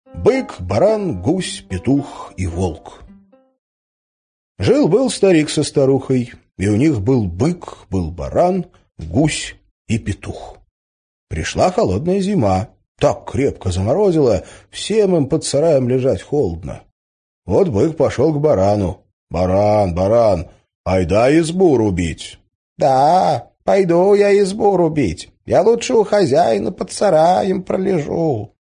Аудиокнига Бык, баран, гусь, петух и волк | Библиотека аудиокниг